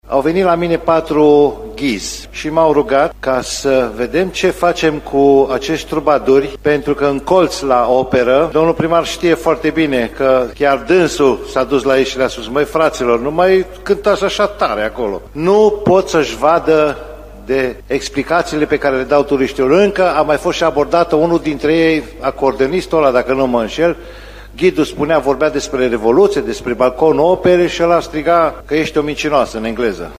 Cântăreții stradali din centrul Timișoarei deranjează grupurile de turiști. Consilierul local, Lucian Căldăraru, susține că mai mulți ghizi turistici din oraș s-au plâns de faptul că nu pot să le vorbească grupurilor de turiști din cauza artiștilor stradali care cântă prea tare pe străzile din centrul istoric: